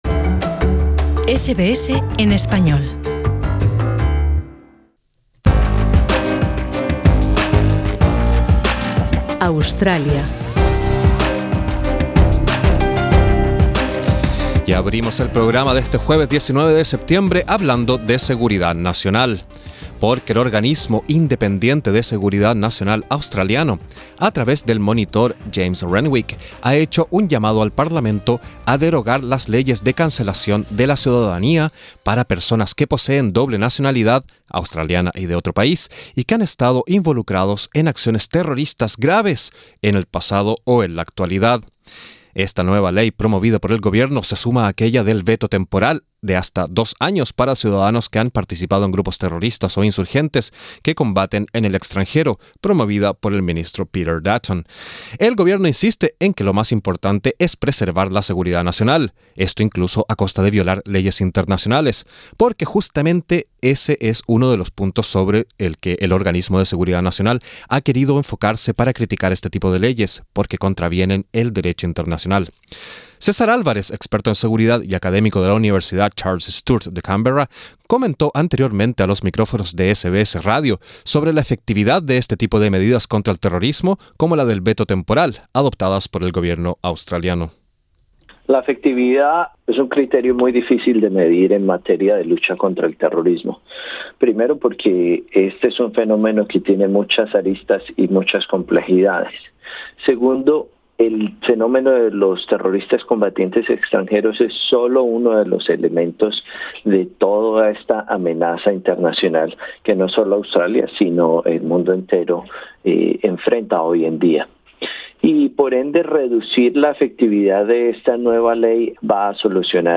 En SBS Radio entrevistamos